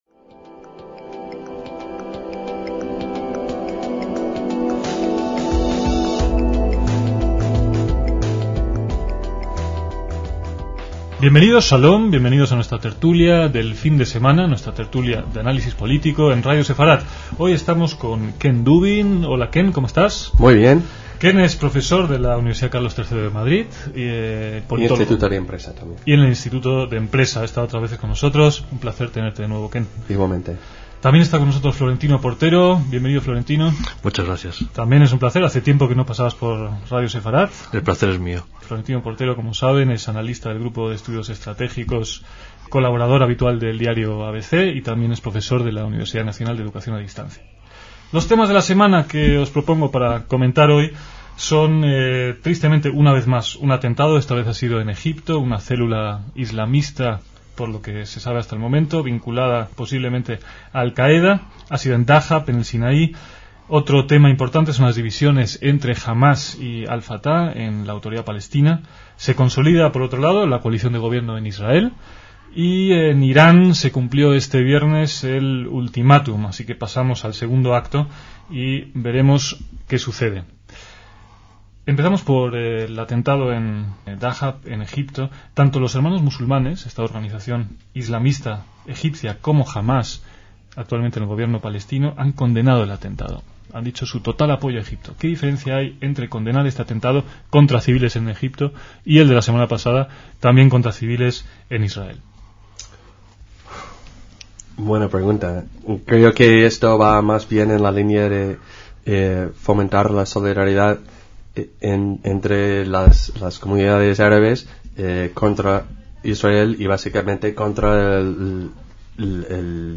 DECÍAMOS AYER (29/4/2006) - Los temas de actualidad de esta tertulia de 2006 fueron un atentado yihadista en Egipto, las divisiones entre Fatah y Hamás en los territorios de la Autoridad Palestinja y la consolidación de la nueva coalición de gobierno israelí, además del final del ultimatum al regimen iraní.